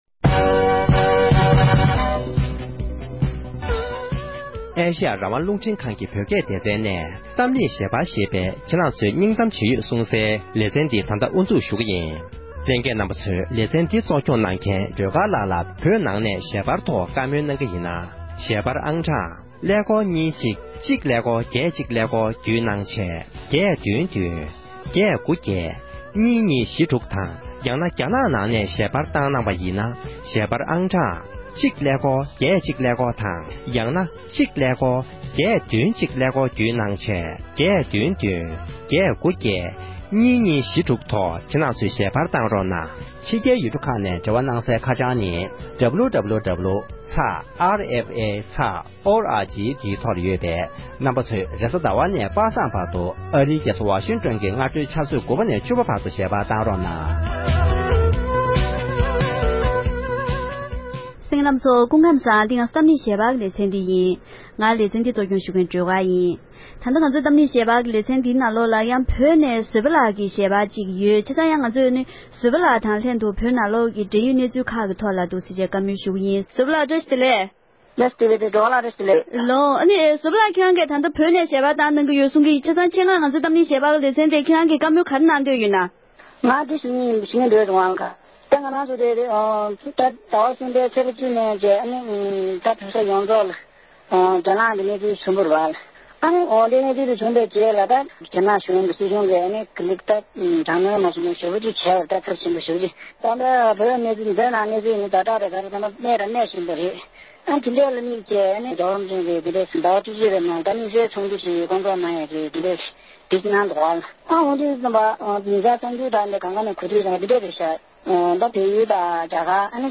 བོད་ཀྱི་རྒྱལ་ས་ལྷ་ས་ནས་བོད་མི་ཞིག་གིས་བོད་ནང་གི་གནས་སྟངས་དང་འཚོགས་འཆར་ཡོད་པའི་དམིགས་བསལ་ཚོགས་འདུའི་ཐོག་དགོངས་ཚུལ་གསུངས་པ།